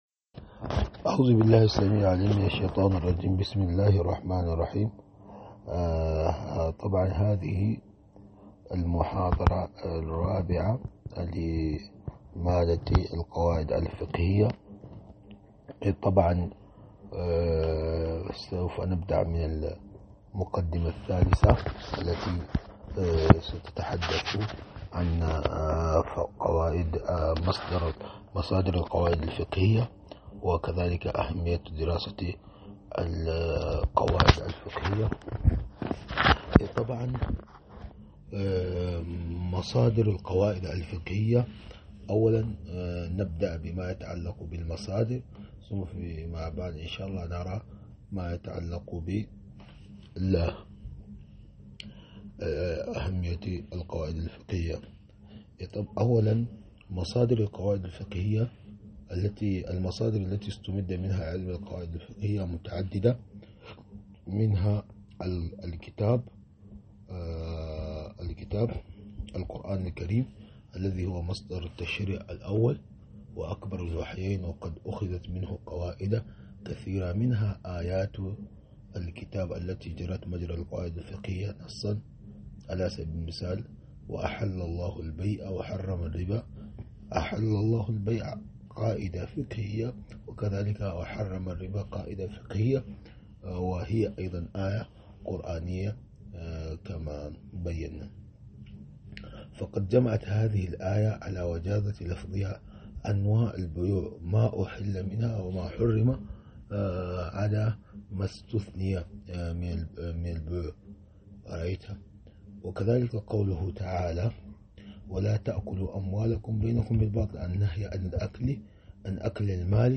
محاضرة مادة القواعد الفقهية 004